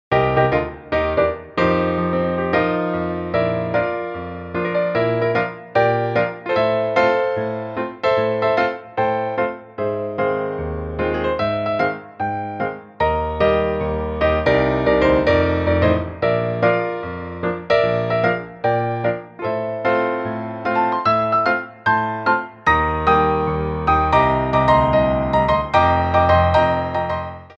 Medium Allegro 2
4/4 (16x8)